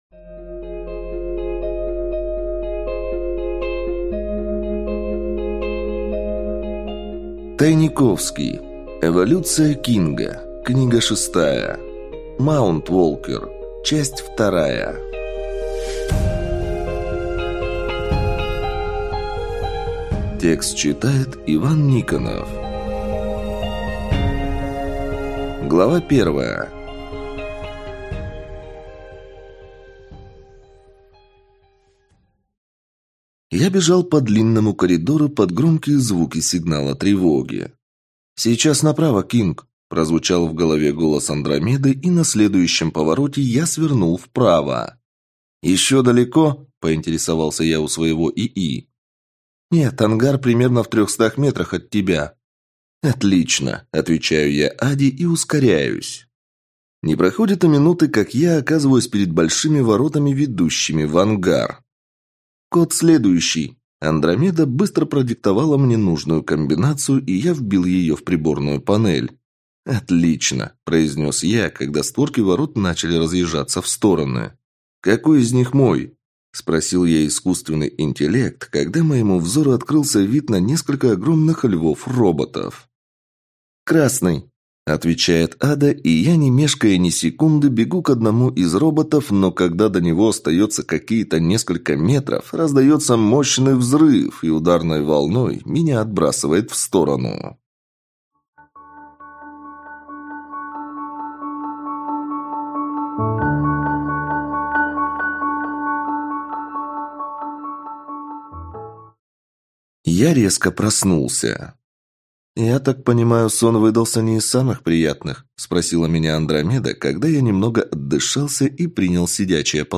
Аудиокнига Маунтволкер (II) | Библиотека аудиокниг